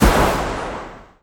Add ghost sounds.